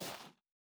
Bare Step Snow Medium A.wav